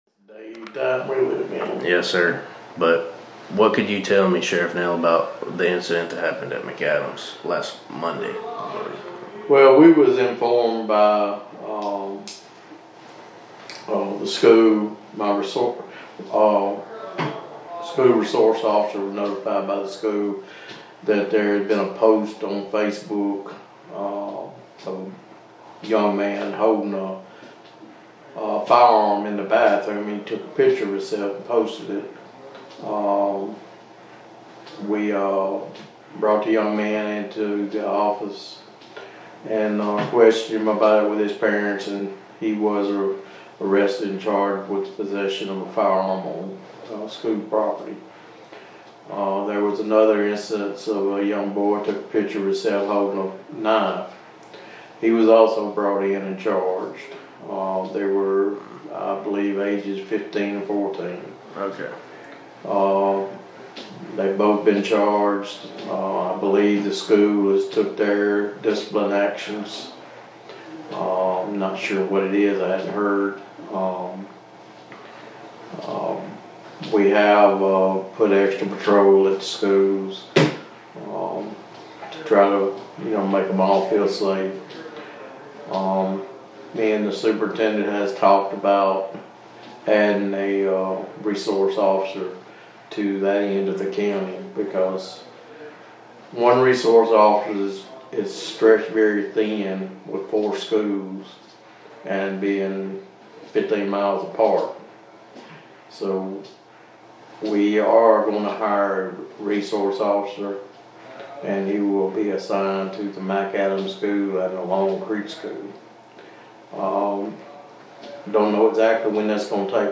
Audio of interview with Sheriff Nail regarding the weapons incidents at McAdams High School.